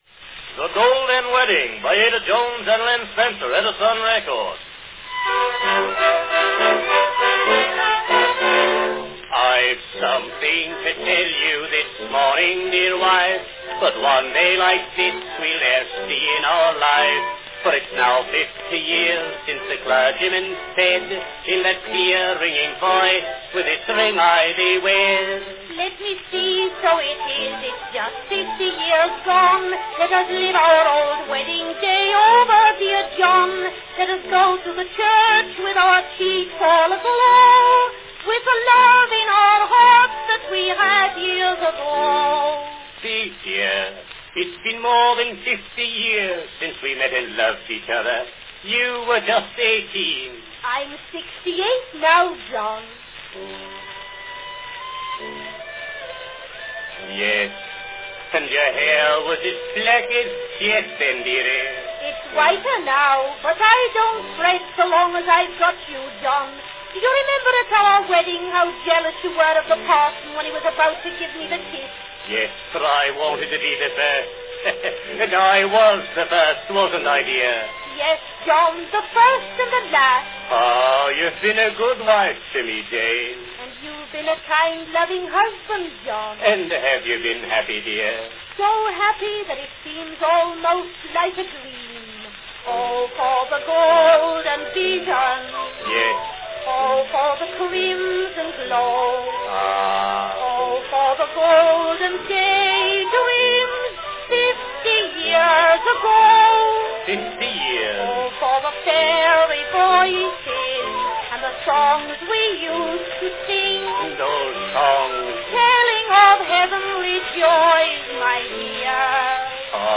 A vaudeville specialty from 1905
Category Vaudeville
with orchestra accompaniment